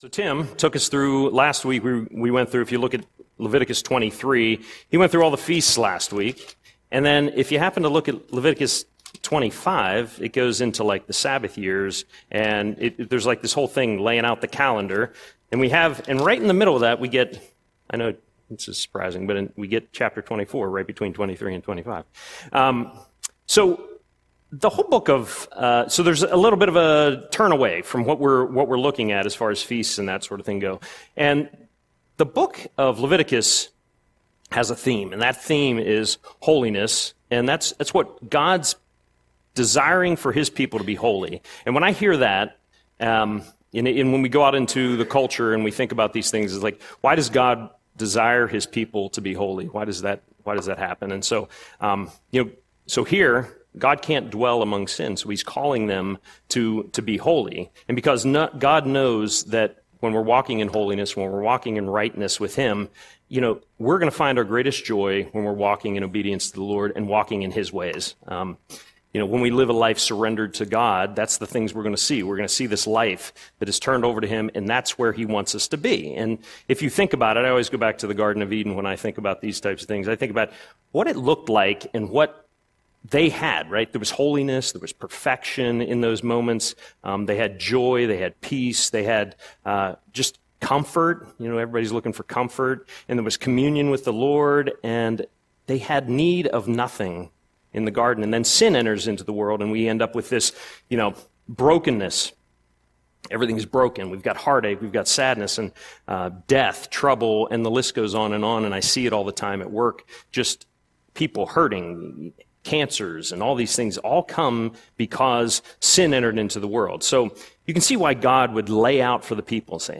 Audio Sermon - January 14, 2026